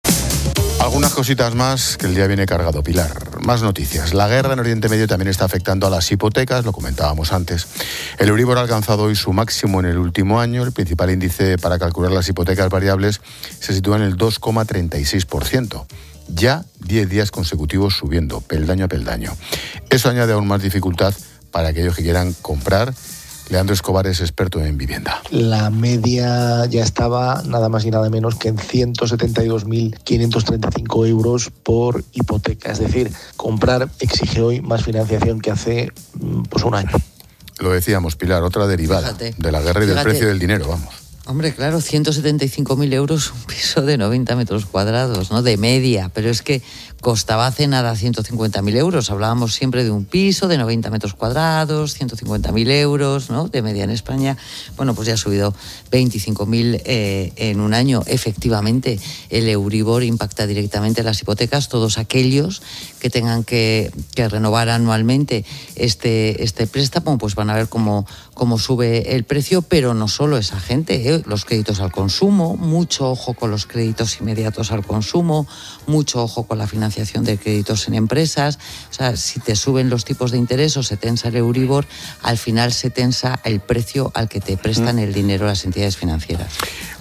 Expósito aprende en Clases de Economía de La Linterna con la experta económica y directora de Mediodía COPE, Pilar García de la Granja, sobre el impacto de la guerra en las hipotecas y el Euríbor